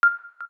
KEYTONE1_0.wav